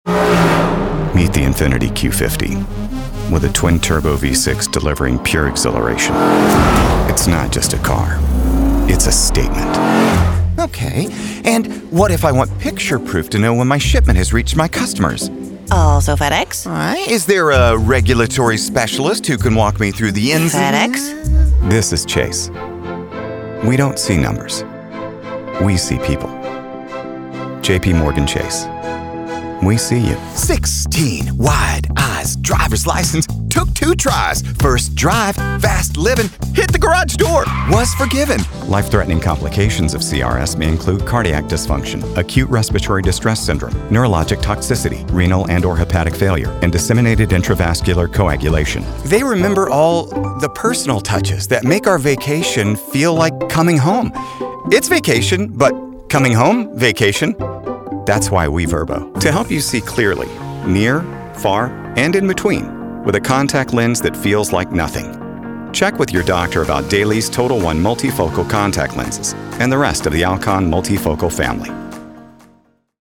Dynamic, Friendly, Engaging
All-American Voice
Commercial